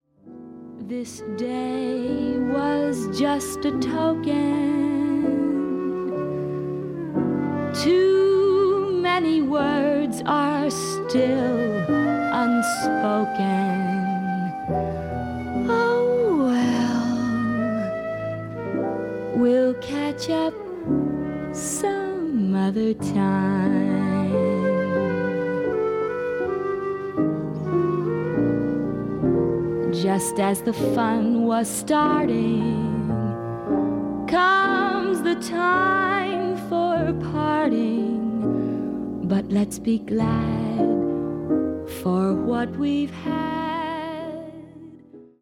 この時代の歌手ならではの明朗快活っぷりに、深く息をするようなデリケートな歌い方もできるアメリカのシンガー
恋をテーマにしたスロー〜ミディアム・テンポの曲でほぼ占められる本作。